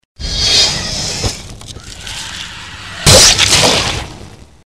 Звуки чужого
Звук при зарезании чужого